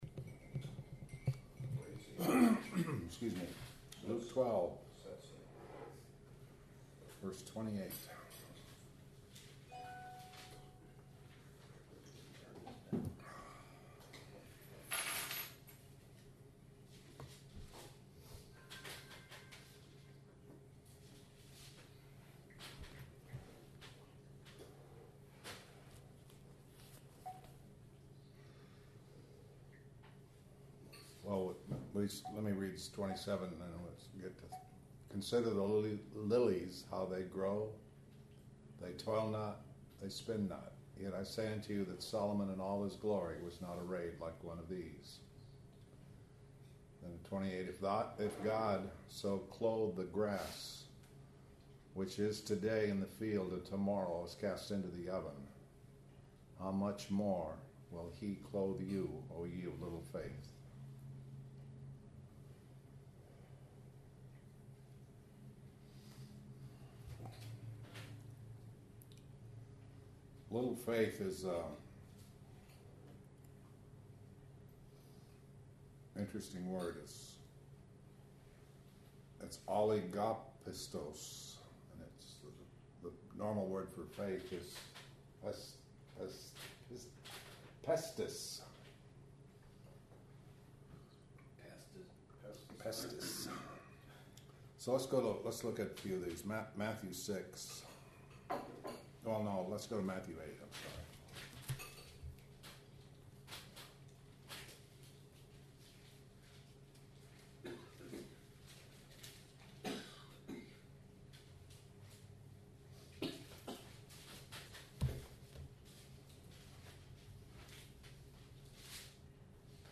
Feb. 19, 2014 – Luke 12:28 Posted on June 24, 2014 by admin Feb. 19, 2014 – Luke 12:28 “Little faith” Matthew 8:23-26, Matthew 14:22-32 Luke 12:29 – neither be ye of doubtful mind (puffed up, lifted up, prideful). Matthew 16:1-12 This entry was posted in Morning Bible Studies .